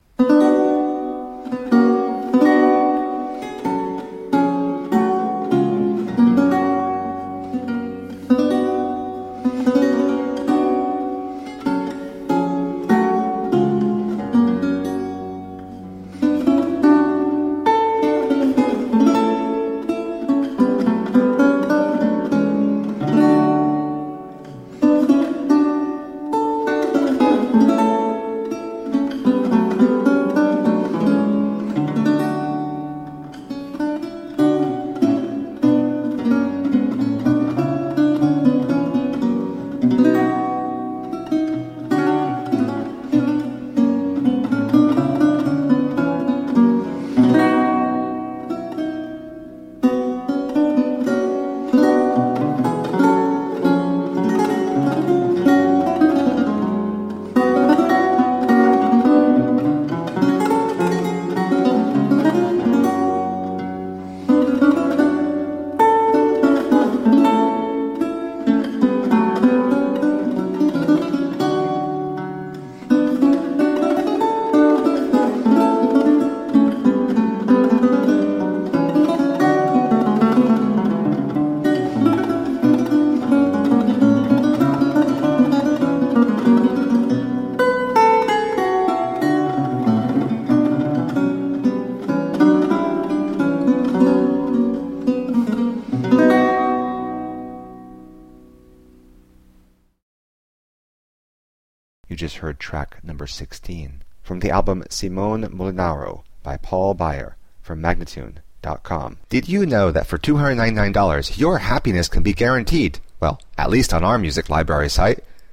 Solo lute of the italian renaissance..